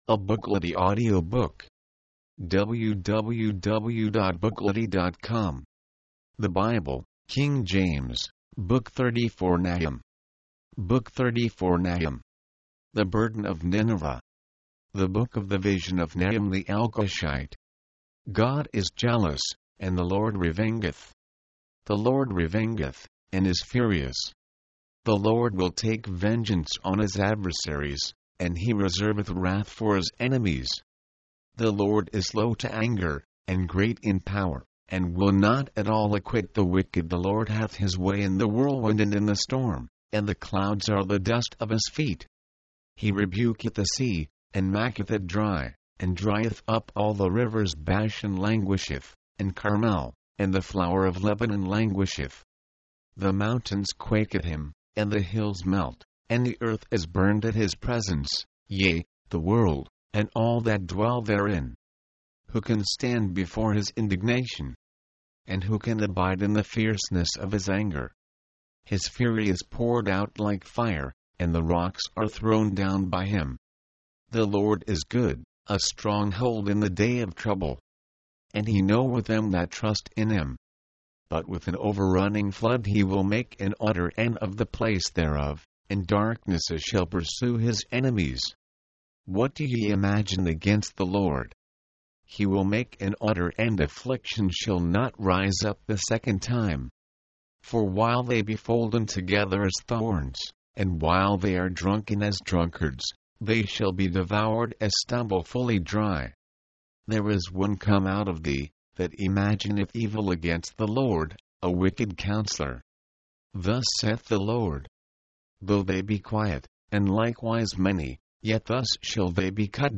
Nahum announced that God would destroy the people of Nineveh because of their cruelty in war. mp3, audiobook, audio, book